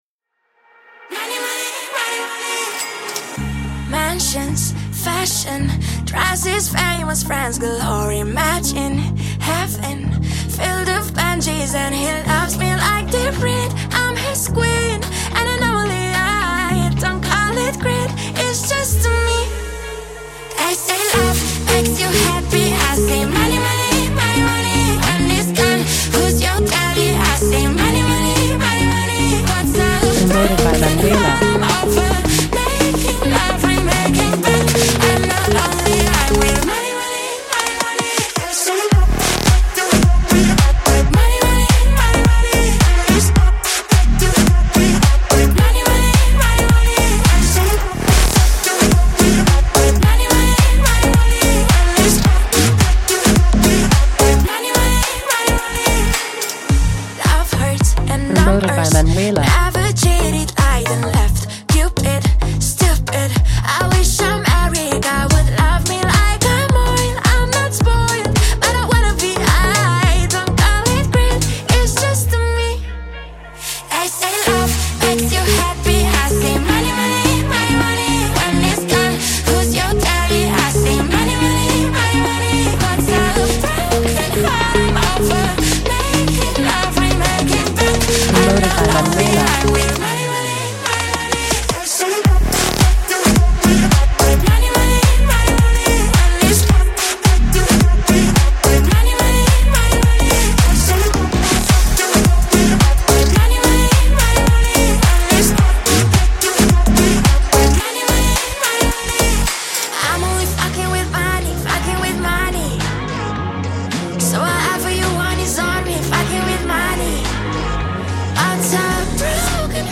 chanson joyeuse
Radio Edit